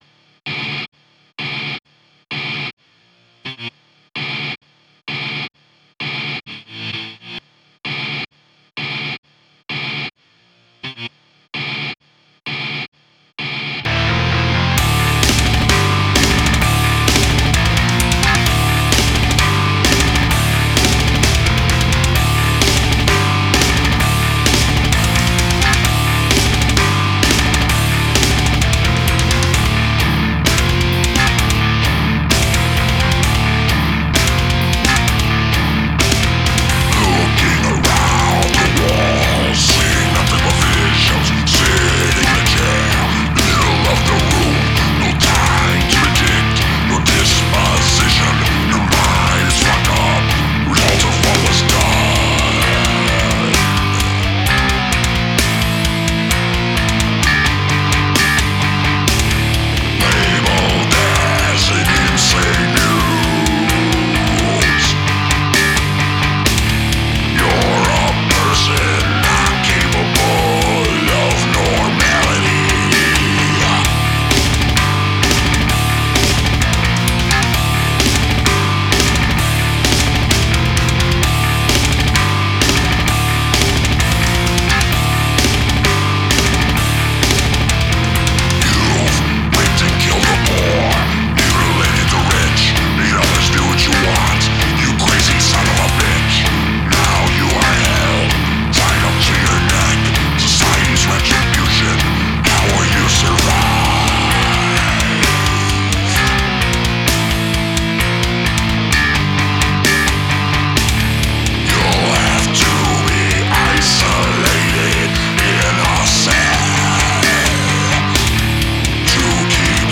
New Death/Elctro Song